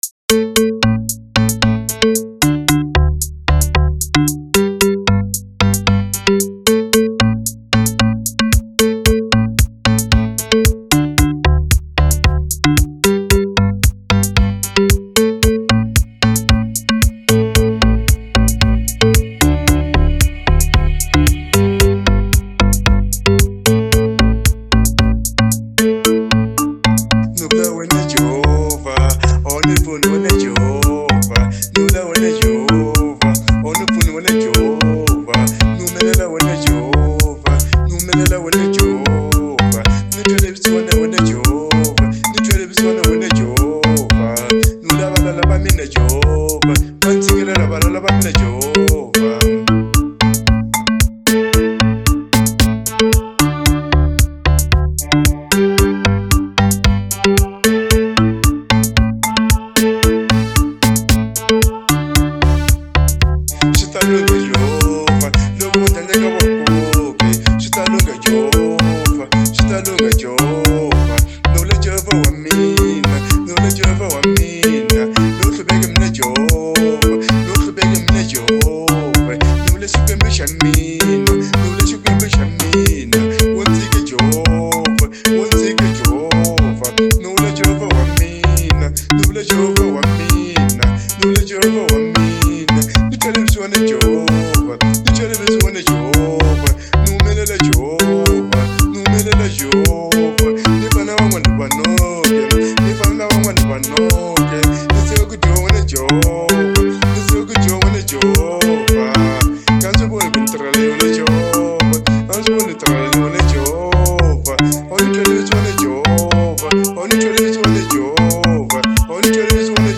06:13 Genre : Xitsonga Size